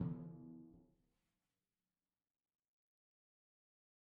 Timpani4_Hit_v2_rr1_Sum.mp3